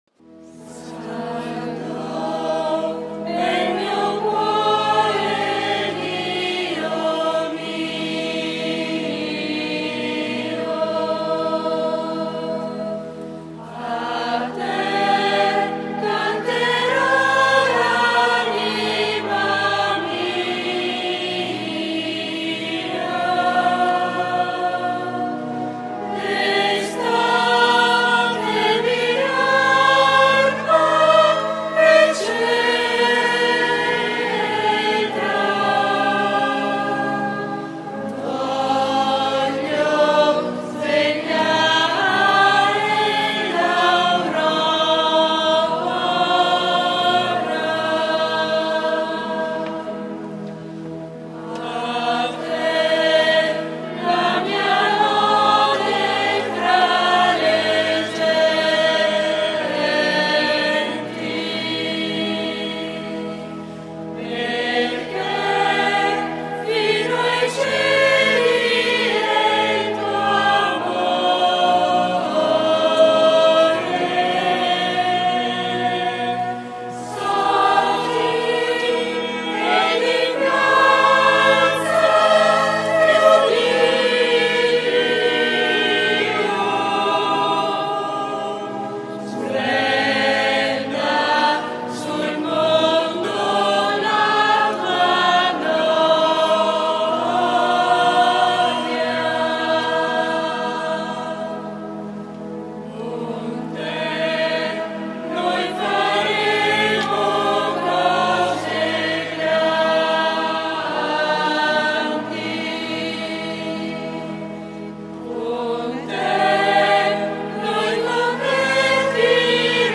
SOLENNITÀ DI CRISTO RE